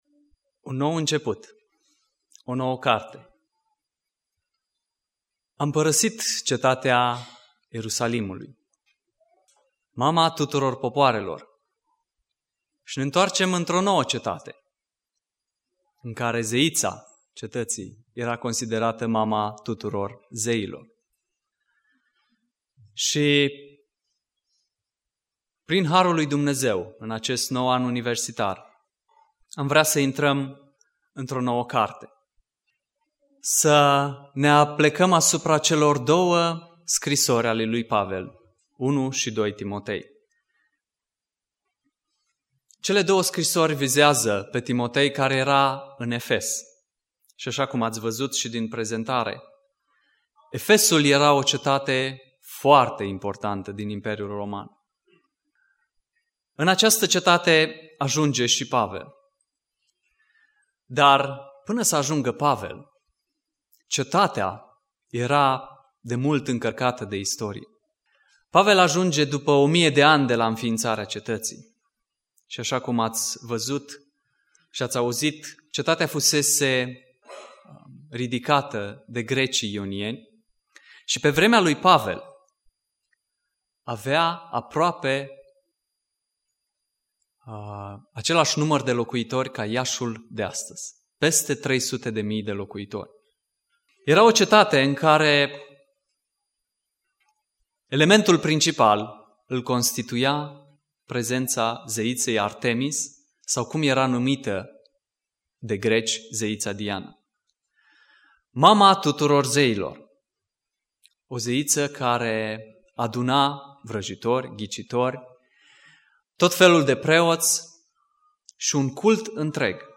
Predica Exegeza - Introducere 1 Timotei